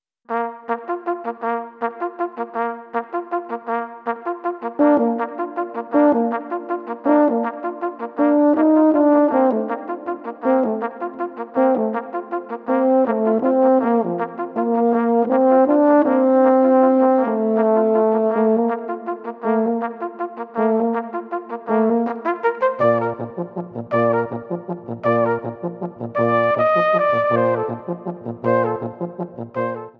Gattung: für Flügelhorn und Horn
Besetzung: VOLKSMUSIK Weisenbläser